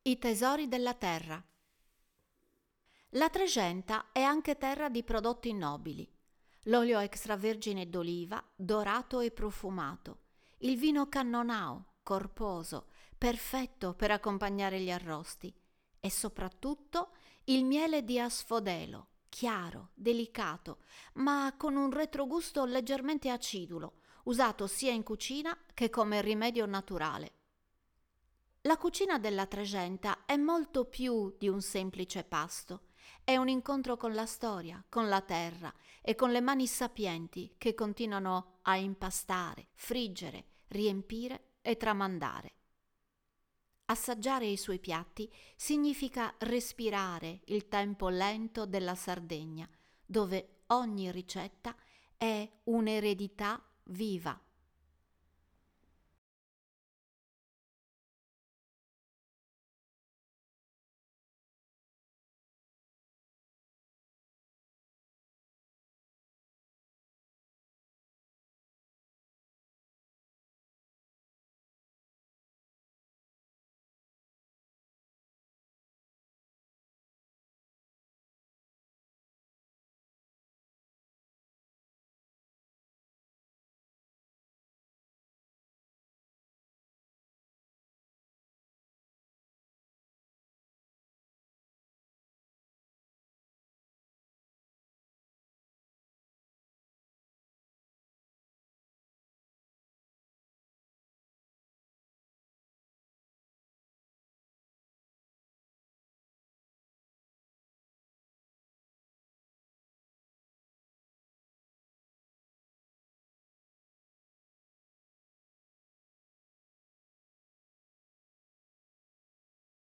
Voce Narrante
🎧 Audioguida - Sapori della Trexenta